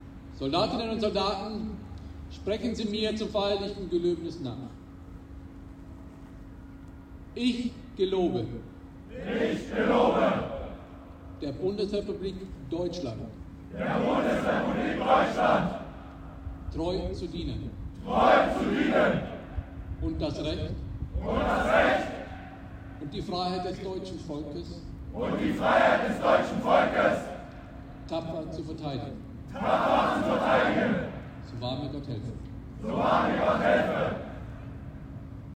Versprechen auf dem Antreteplatz: Rekrutinnen und Rekruten des Panzerpionierbataillons 701 sprechen die Gelöbnisformel. Damit versprechen sie, der Bundesrepublik Deutschland treu zu dienen und die Demokratie tapfer zu verteidigen.
das Feierliche Gelöbnis